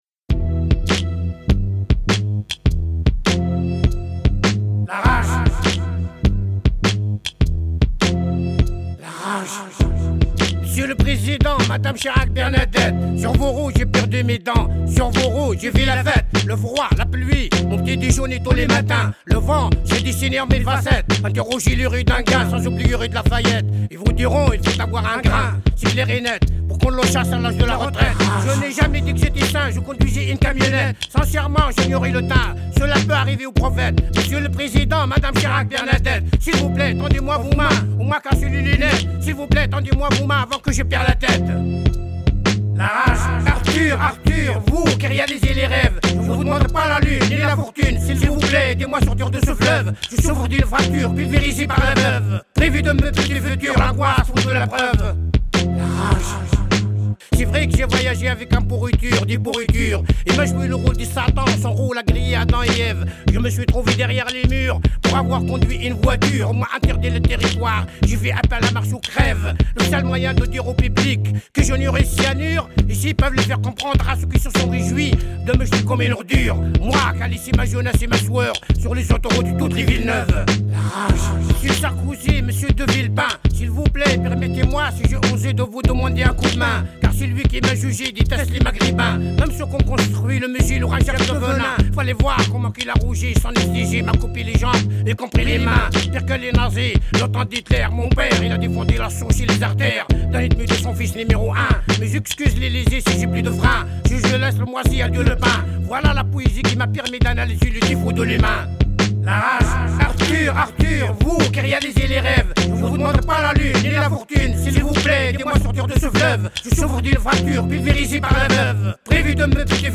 Émission